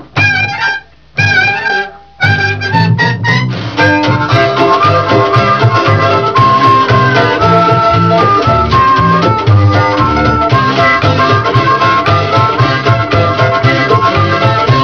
try the sound of our washboard